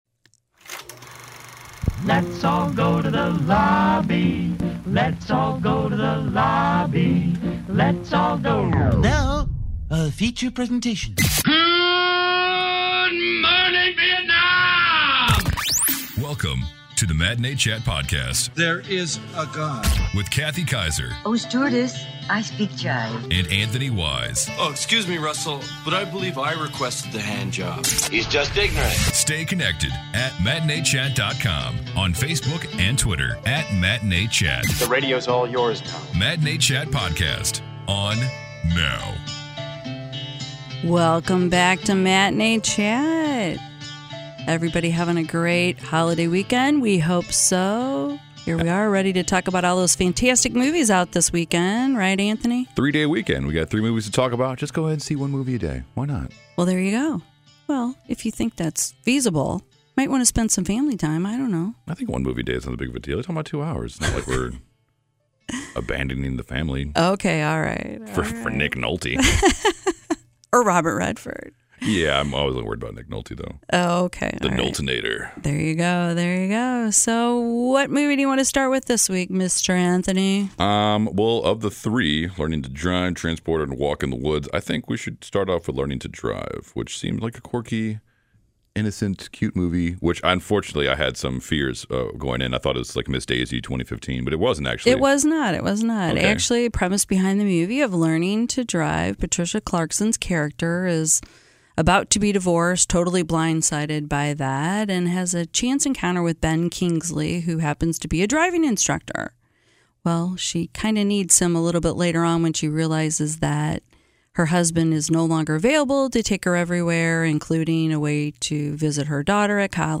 just share below what movie the tune played during the podcast was from….